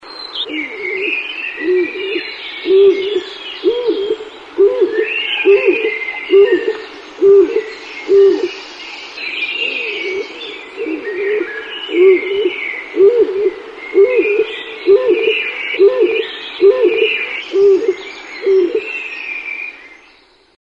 Canto de la paloma zurita
canto-paloma-zurita.mp3